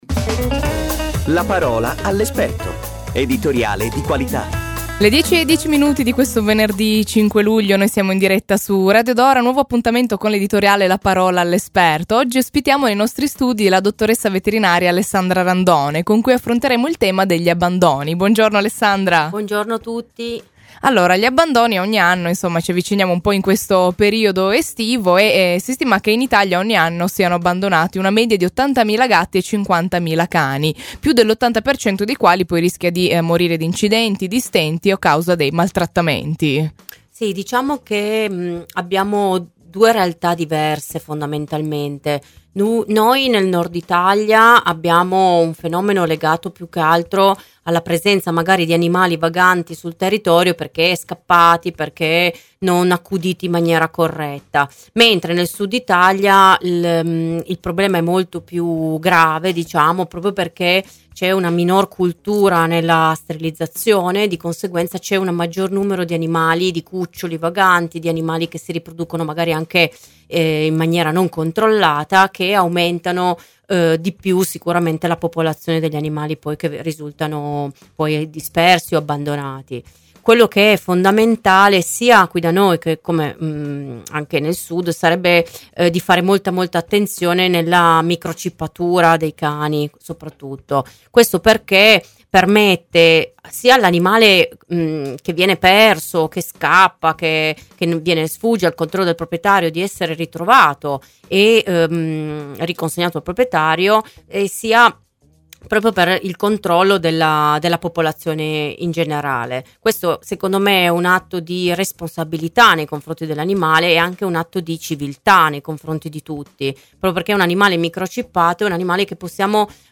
Home Intervista Abbandoni e caldo torrido: i consigli per proteggere i nostri amici a quattro zampe 5 Luglio 2019 Abbandoni e caldo torrido